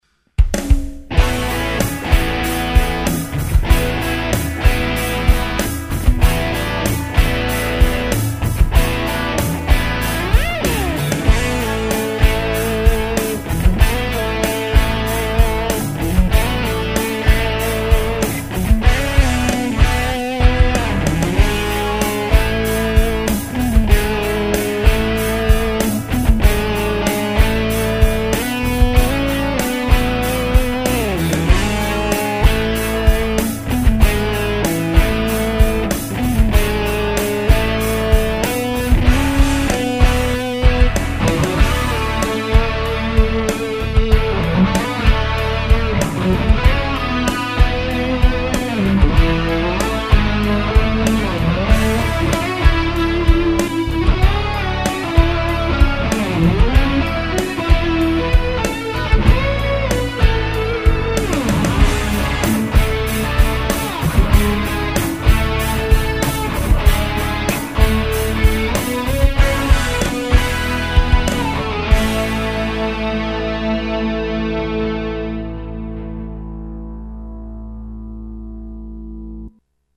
Guitarra